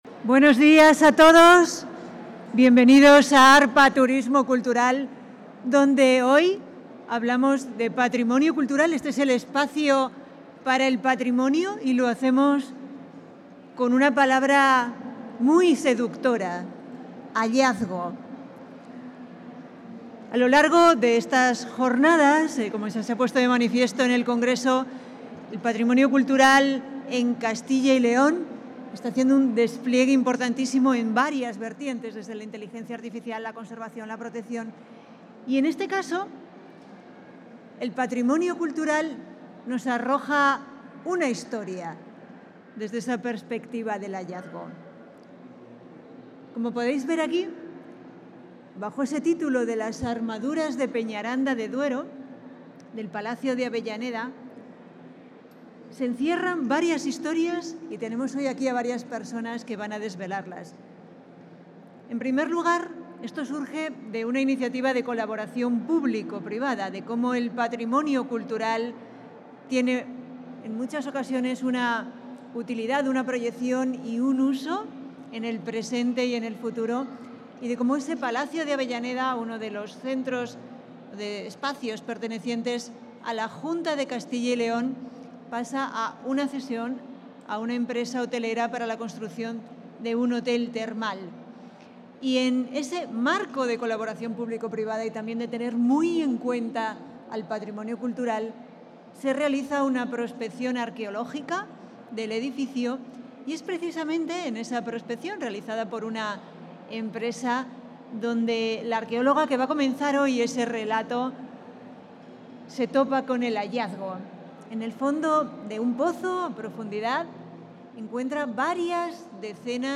Intervención de la viceconsejera.
Algunas de las piezas de este hallazgo y el proyecto de restauración de todo el conjunto, ya iniciado, se han presentado hoy en el marco de la Feria AR-PA Turismo Cultural, que se celebra has mañana de forma simultánea con INTUR.